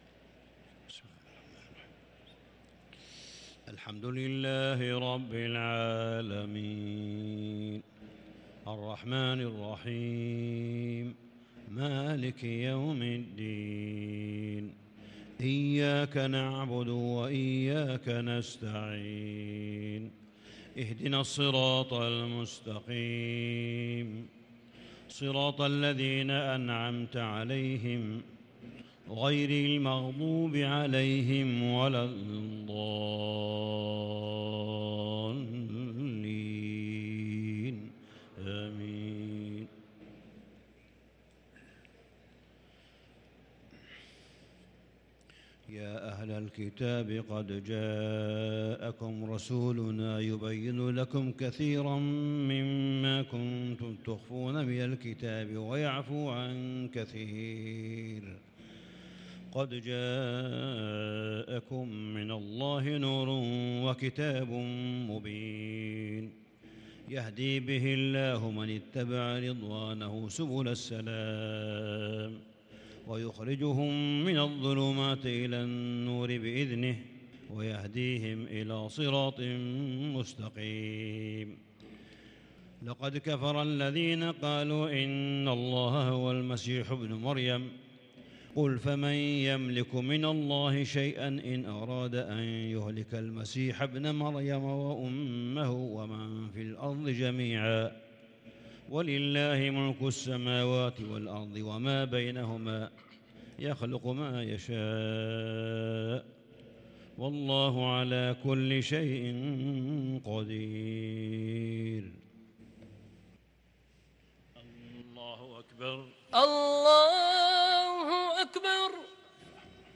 صلاة العشاء للقارئ صالح بن حميد 25 رمضان 1443 هـ
تِلَاوَات الْحَرَمَيْن .